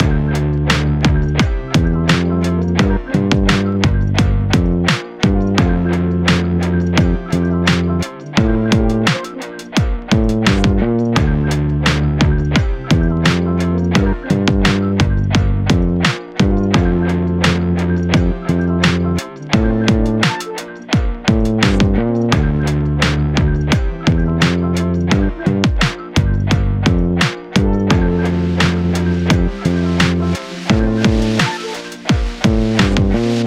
Chill
D Minor
Lofi Air
Synthy Jaco
Emo